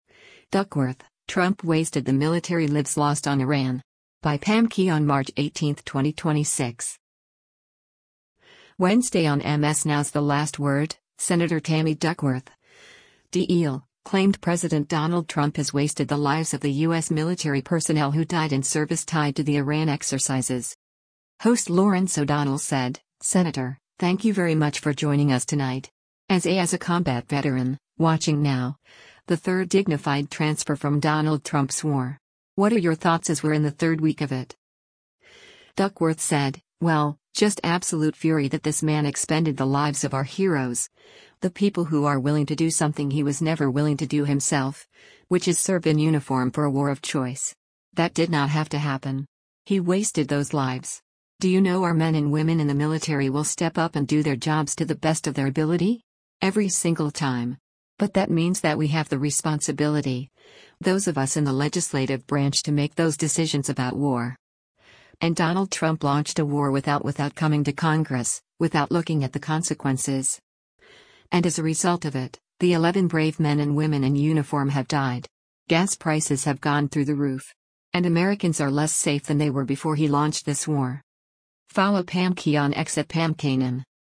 Wednesday on MS NOW’s “The Last Word,” Sen. Tammy Duckworth (D-IL) claimed President Donald Trump has “wasted” the lives of the U.S. military personnel who died in service tied to the Iran exercises.